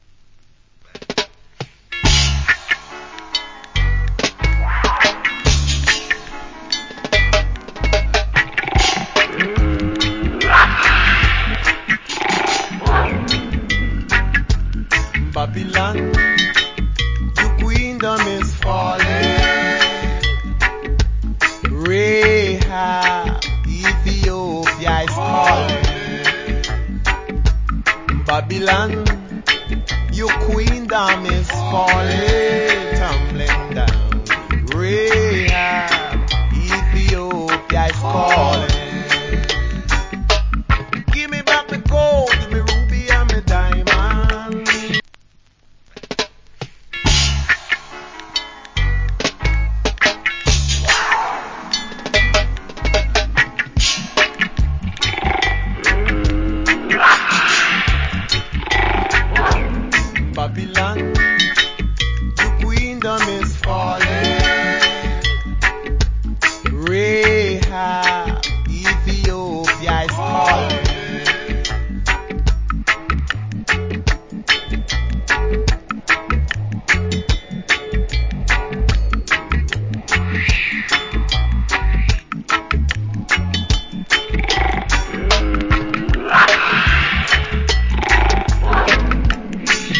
Good Roots Rock Vocal.